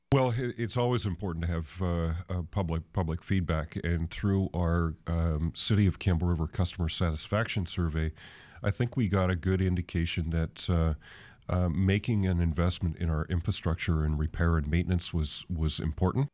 Mayor Andy Adams says engaging residents was key to developing the financial plan.